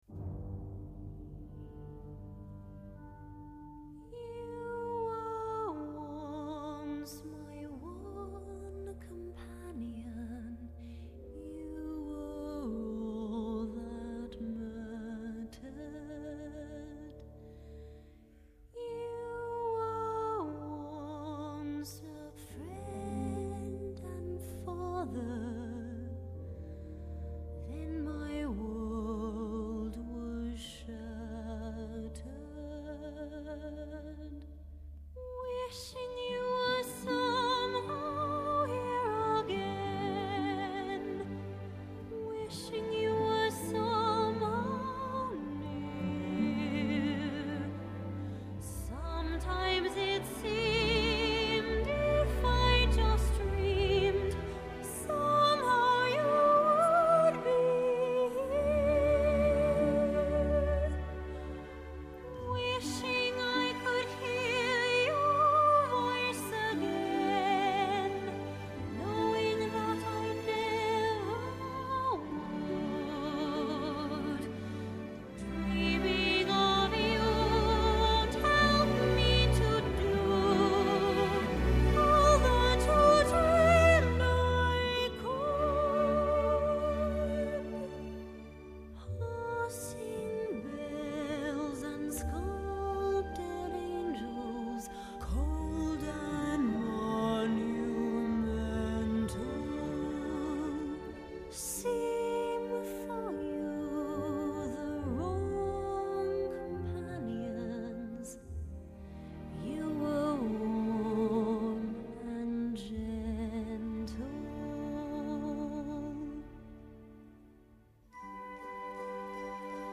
音乐类型：电影配乐